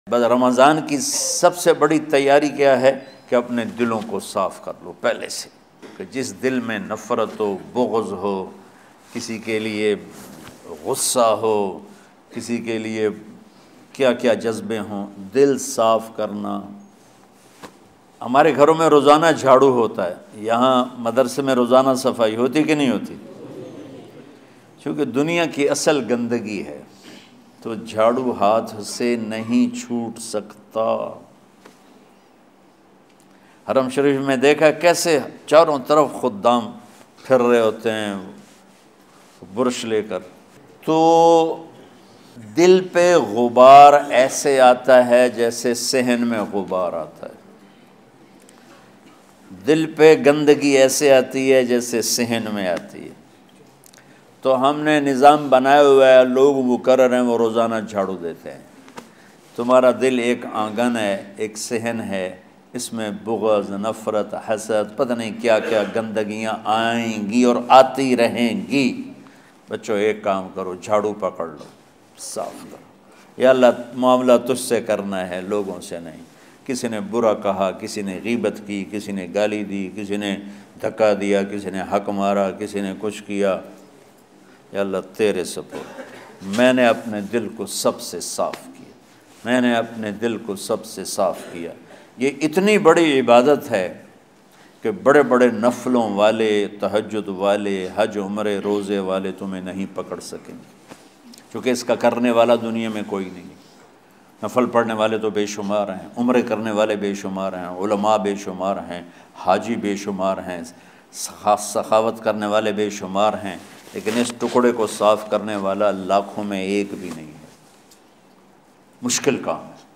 Ramzan Ki Taiyari Kaise Karen, listen or play online latest bayan about Ramadan Kareem in the voice fo Maulana Tariq Jameel.